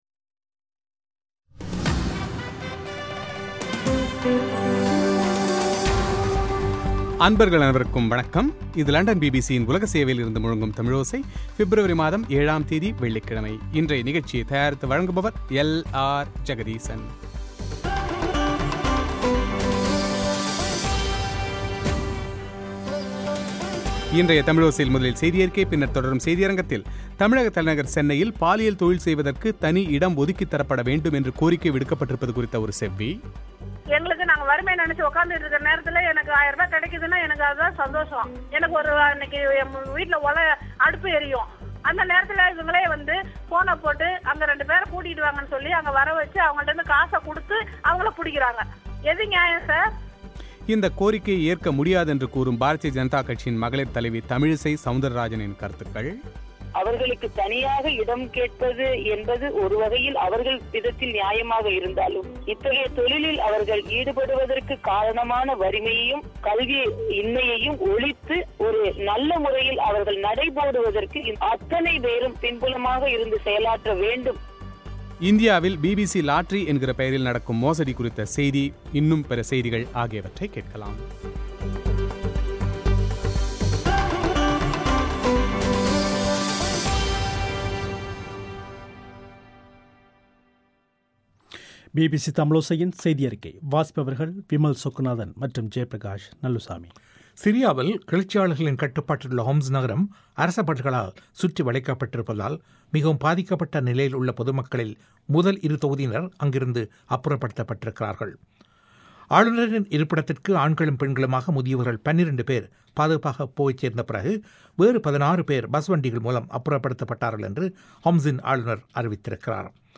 தமிழக தலைநகர் சென்னையில் பாலியல் தொழில் செய்வதற்கு தனி இடம் ஒதுக்கித் தரவேண்டும் என்று கோரிக்கைவிடுக்கப்பட்டிருப்பது குறித்த செவ்வி;